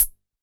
RDM_TapeA_SR88-ClHat.wav